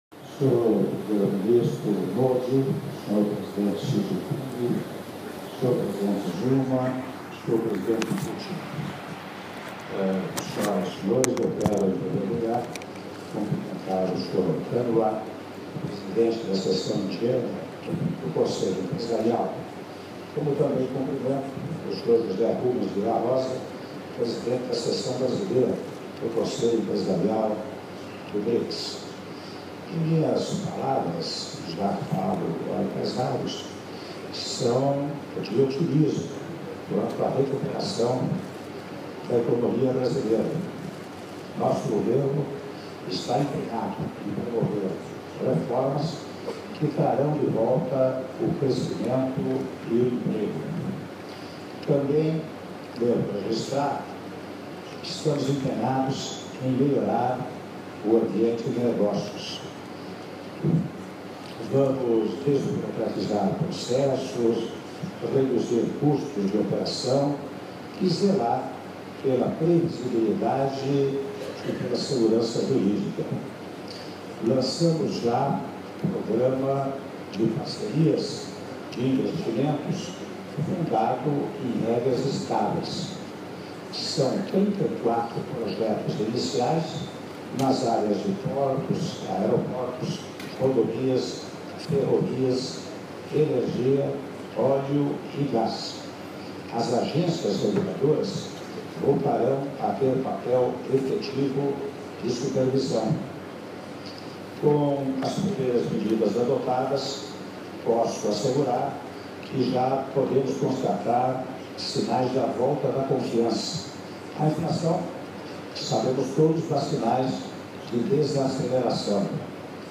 Áudio das palavras do senhor presidente da República, Michel Temer, em reunião dos Chefes de Estado do BRICS com o Conselho Empresarial do BRICS - (04min41s) - Goa/Índia